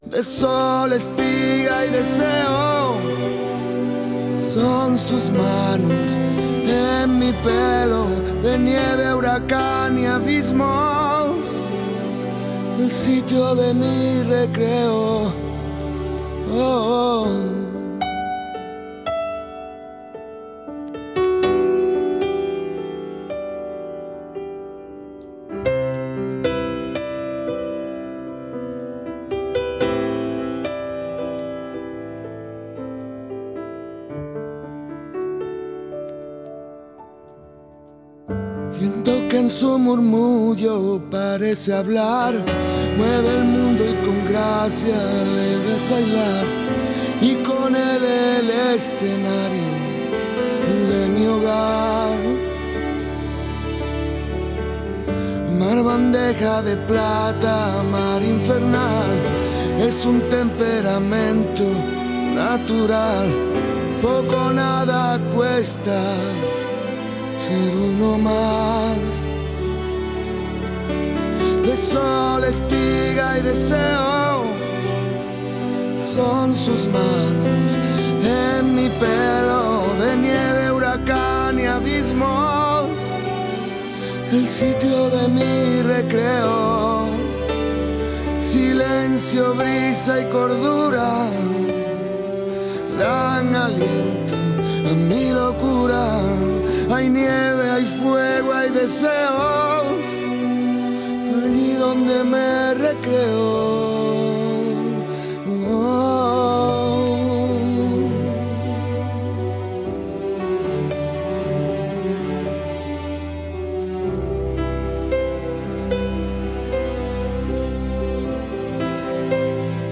Las aplicaciones de citas, a debate - La tarde con Marina